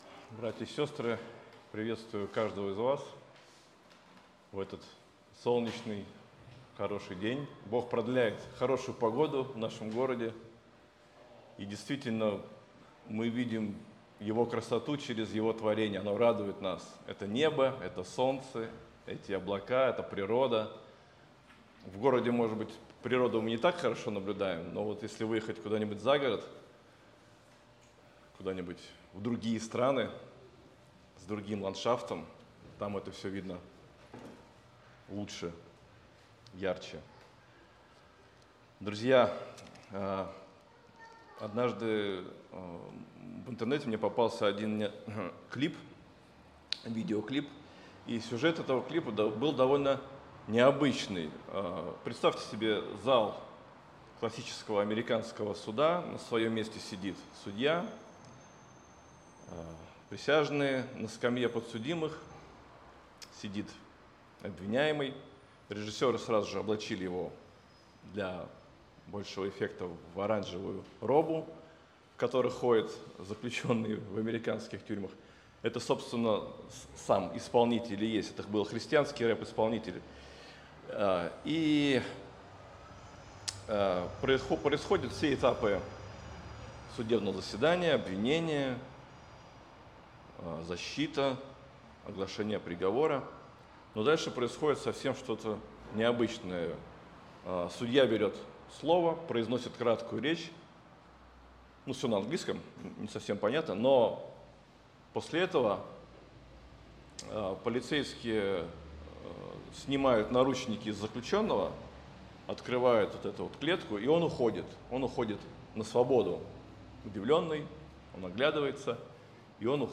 Евангелие от Иоанна 5 часть Проповедник